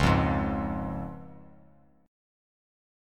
C# Chord
Listen to C# strummed